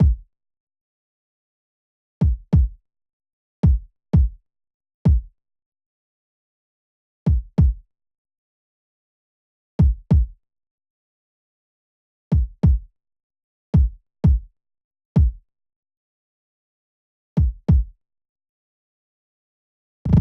AV_Bush_Kicks_95bpm
AV_Bush_Kicks_95bpm.wav